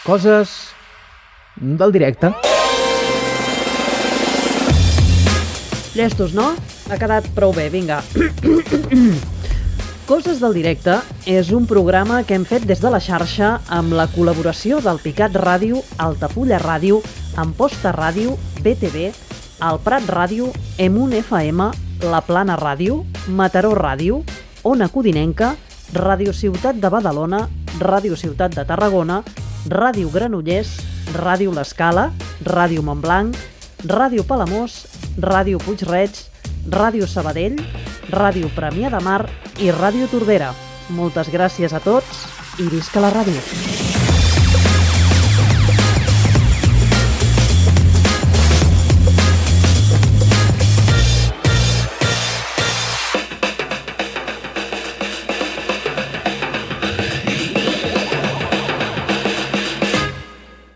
Careta final del programa, amb el llistat d'emissores que hi han participat
Entreteniment